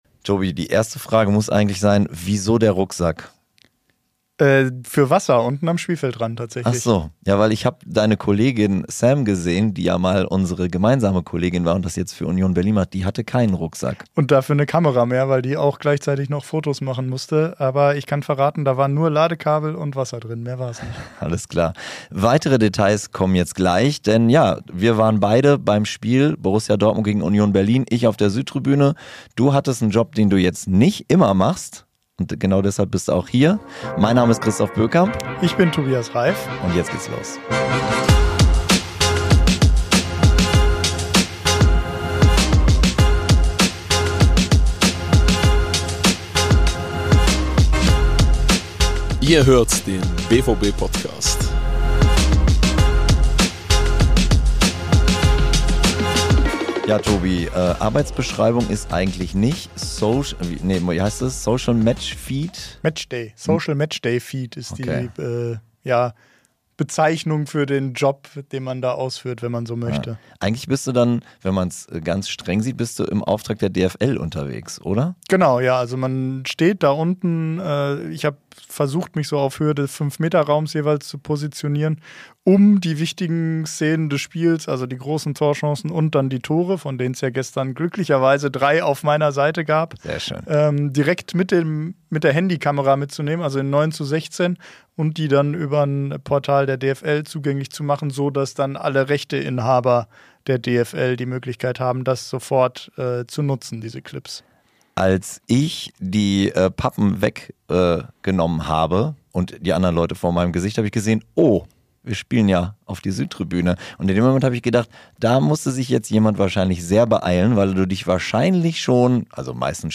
Zwei Podcast-Hosts, die das Heimspiel gegen Union Berlin aus ganz unterschiedlichen Perspektiven gesehen haben: Der eine auf der Südtribüne, der andere vom Spielfeldrand.